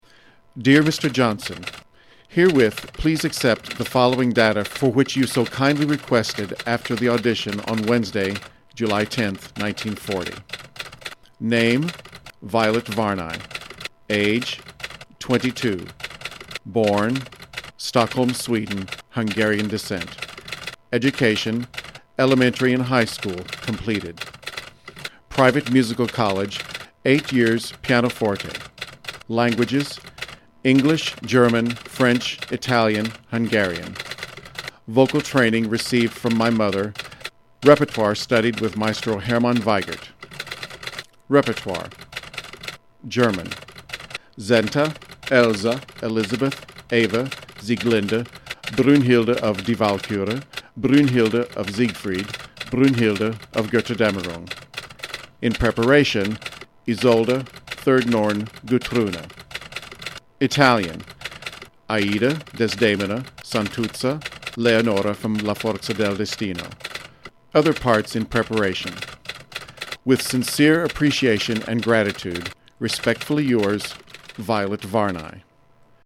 I have included some of her singing and also chose to include two narratives - her letter of introduction to the Met and the saga of saving a Met Götterdämmerung, which are read by me.